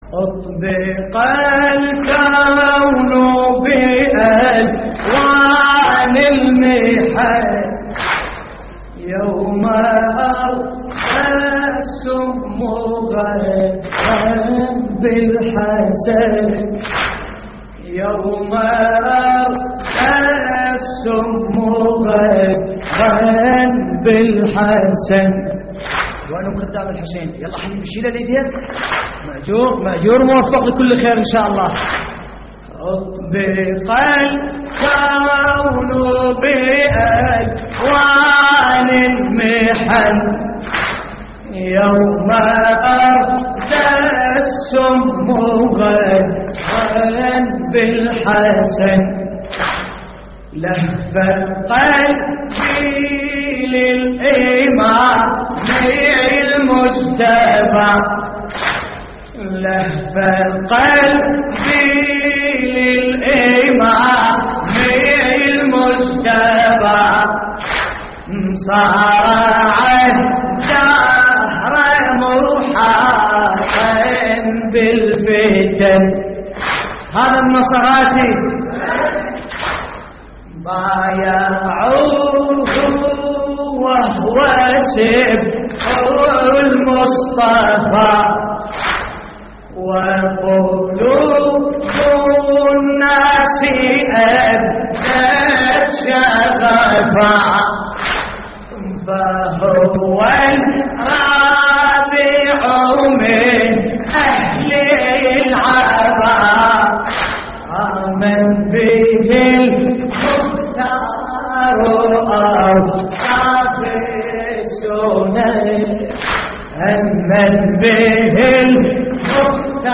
تحميل : اطبق الكون بألوان المحن يوم اردى السم غدرا بالحسن / الرادود باسم الكربلائي / اللطميات الحسينية / موقع يا حسين